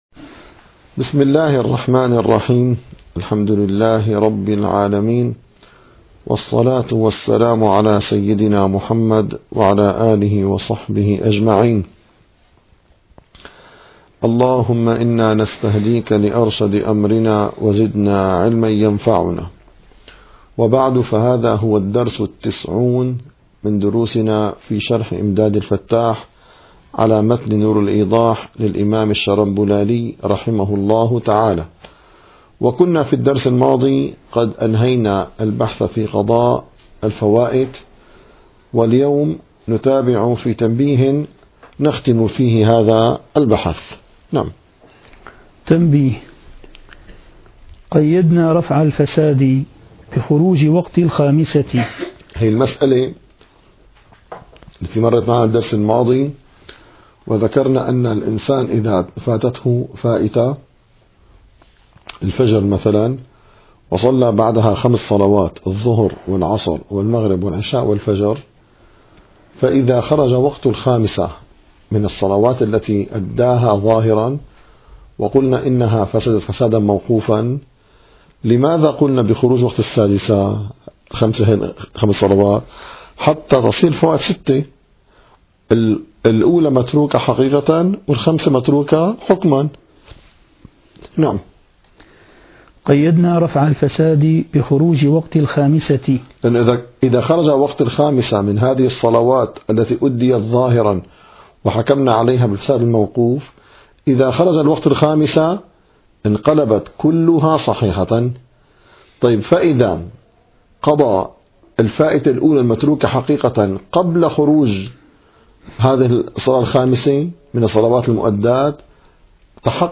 - الدروس العلمية - الفقه الحنفي - إمداد الفتاح شرح نور الإيضاح - 90- تنبيه قيدنا رفع الفساد